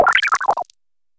Cri de Léboulérou dans Pokémon HOME.